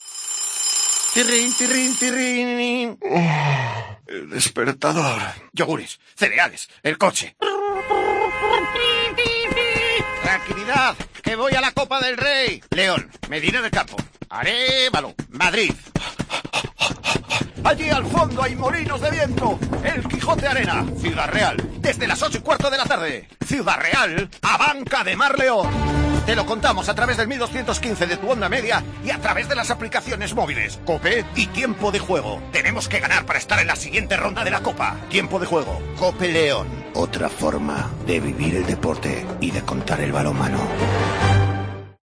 Escucha la cuña promocional del partido Ciudad Real - Ademar el día 15-12-21 a las 20:30 h en el 1.215 OM